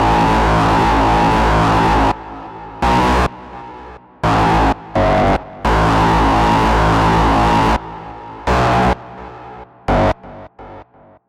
进取的合成器
Tag: 85 bpm Hip Hop Loops Synth Loops 1.92 MB wav Key : Am Cubase